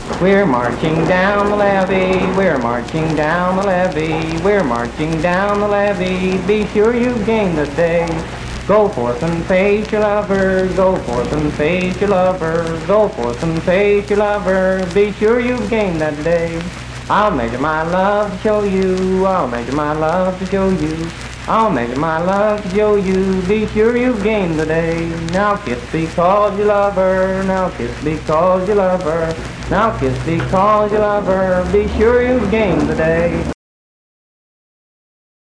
Unaccompanied vocal performance
Dance, Game, and Party Songs
Voice (sung)